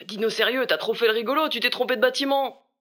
VO_LVL1_EVENT_Mauvais batiment_01.ogg